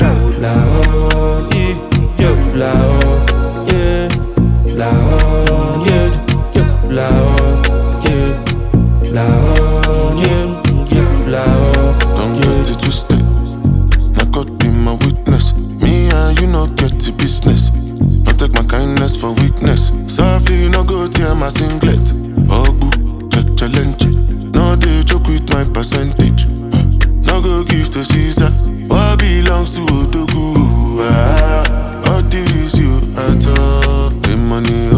Скачать припев
Afrobeats